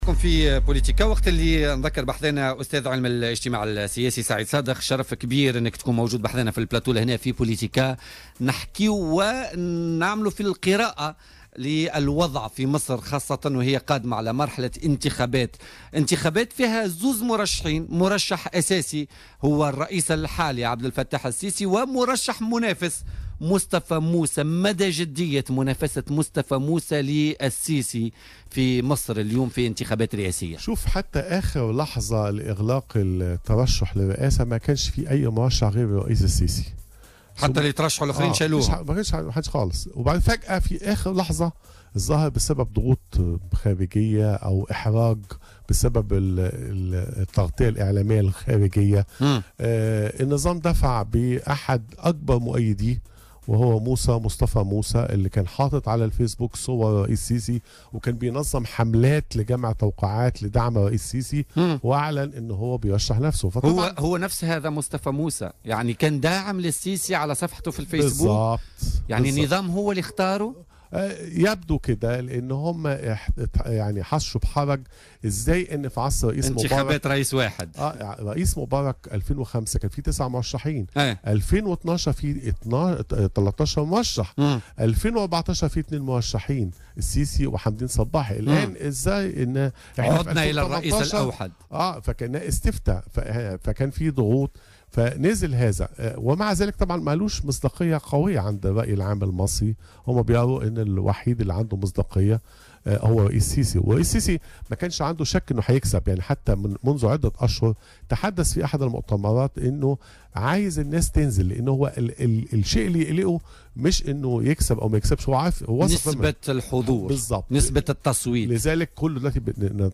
ضيف بولتيكا